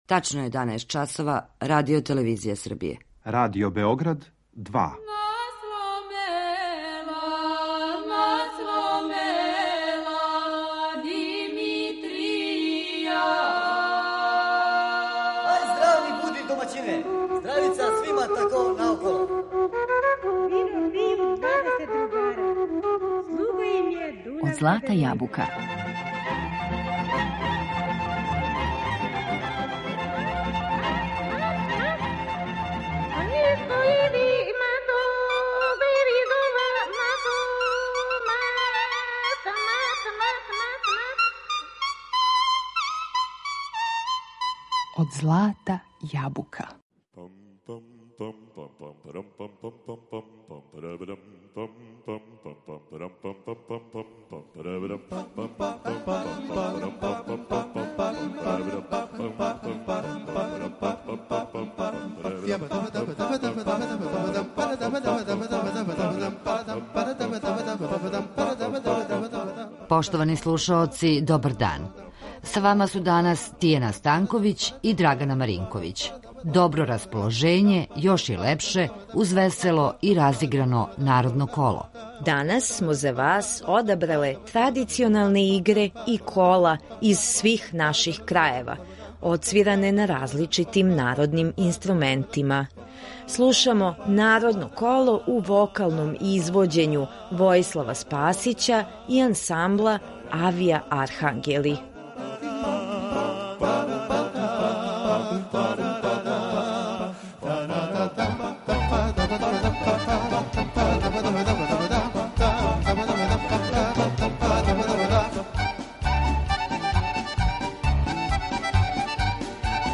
Narodno kolo na različitim instrumentima
U današnjem izdanju emisije Od zlata jabuka odabrale smo tradicionalne igre i kola iz svih naših krajeva, odsvirane na različitim tradicionalnim instrumentima.
harmonici
violinu